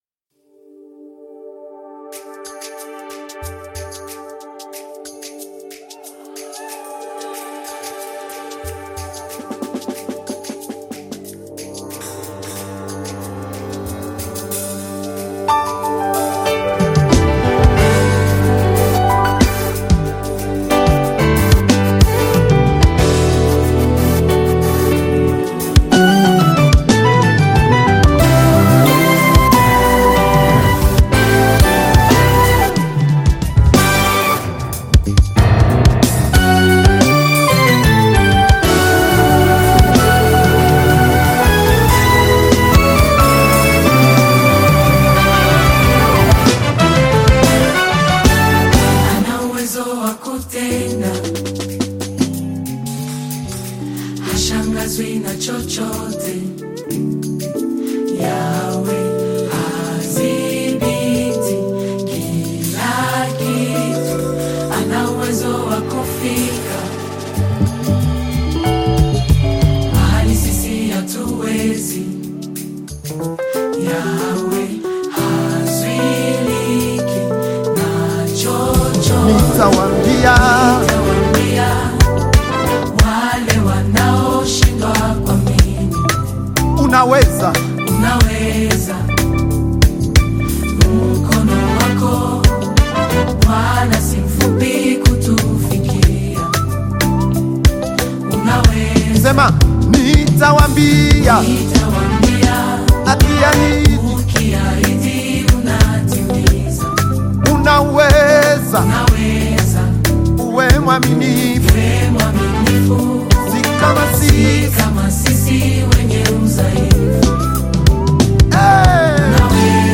Gospel music track
Gospel song